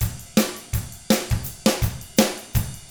164ROCK F4-R.wav